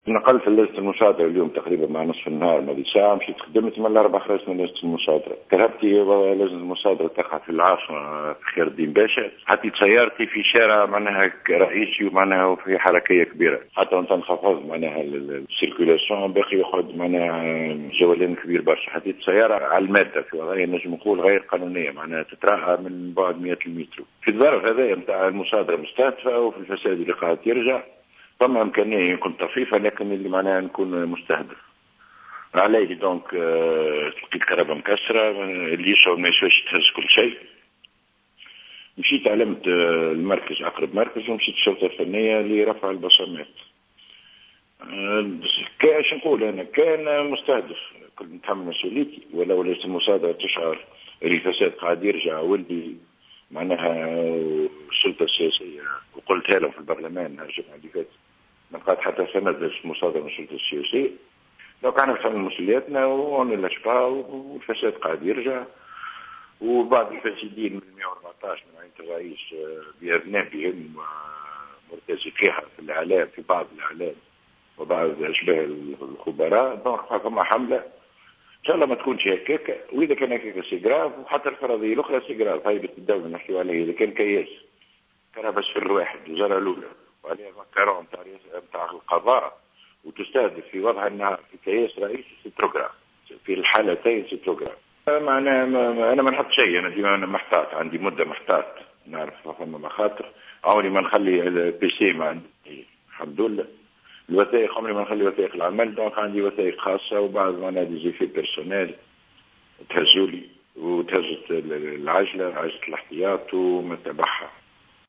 قال عضو لجنة المصادرة،أحمد صواب في تصريح ل"جوهرة أف أم" إن سيارته الإدارية قد تعرّضت اليوم الخميس إلى الخلع عندما كانت رابضة بالقرب من مقر لجنة المصادرة.